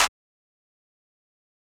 Sicko Clap - Intro.wav